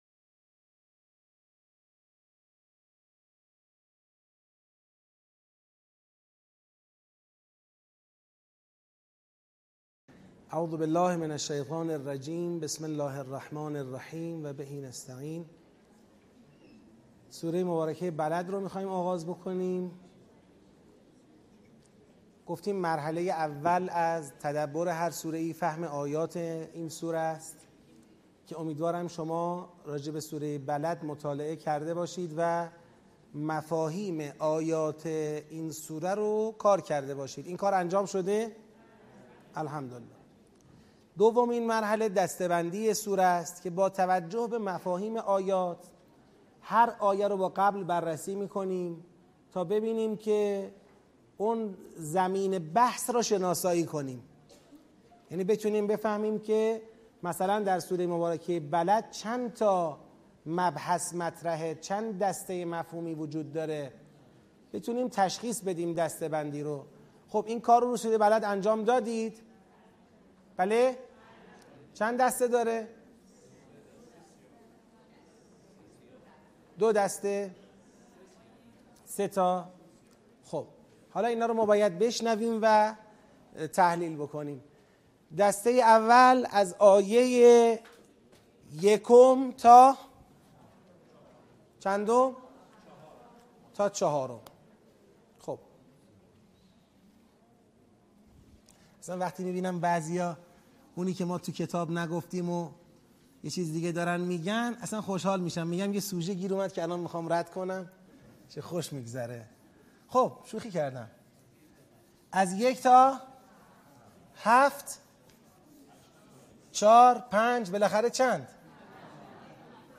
آموزش تدبر در سوره بلد - بخش اول